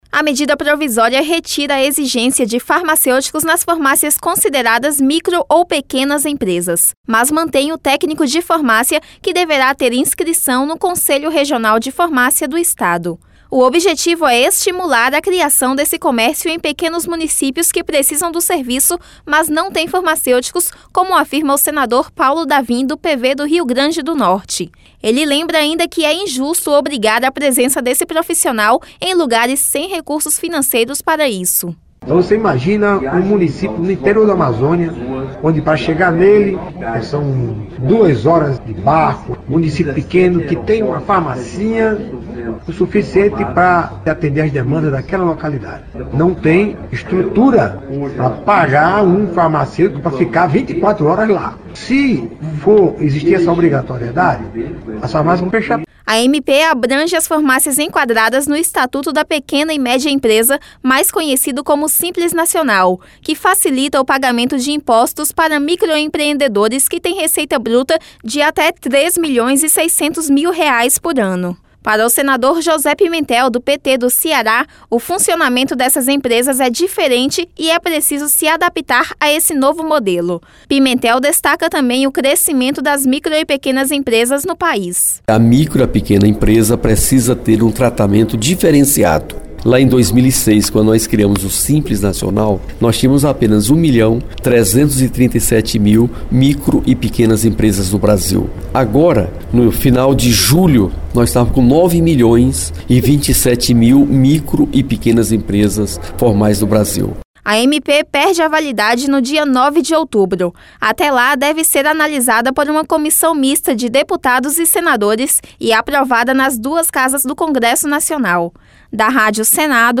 Senador José Pimentel
Senador Paulo Davim